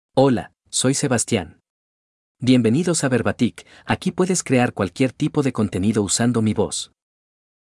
MaleSpanish (Venezuela)
Sebastian is a male AI voice for Spanish (Venezuela).
Voice sample
Male
Sebastian delivers clear pronunciation with authentic Venezuela Spanish intonation, making your content sound professionally produced.